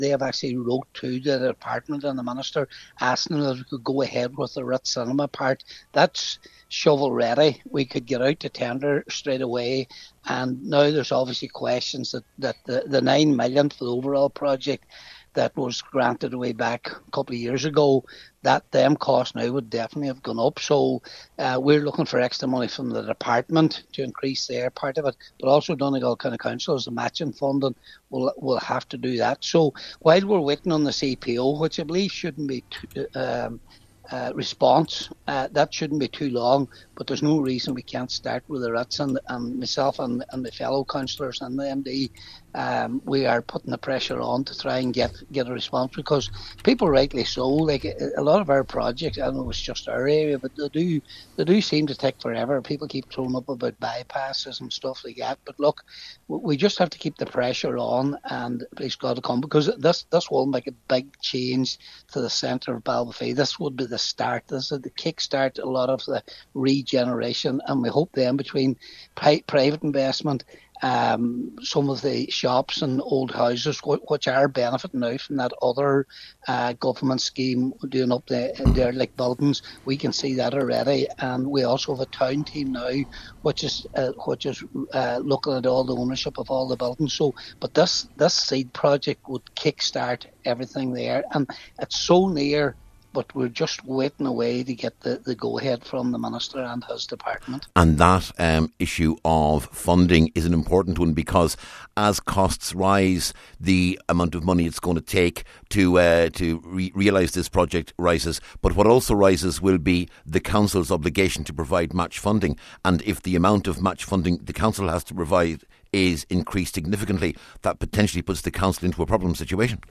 Cllr Patrick McGowan says while the second phase requires some CPOs, officials want to proceed with the Ritz project immediately: